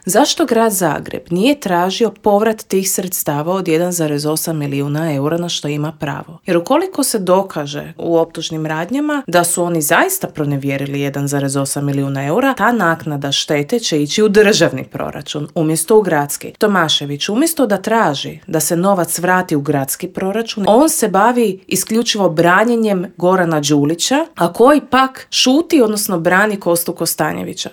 U intervjuu Media servisa ugostili smo jedne od glasnijih kritičara Tomislava Tomaševića i Možemo - nezavisnu zastupnicu u Skupštini Grada Zagreba Dinu Dogan i vijećnika u Vijeću Gradske četvrti Črnomerec Vedrana Jerkovića s kojima smo prošli kroz gradske teme.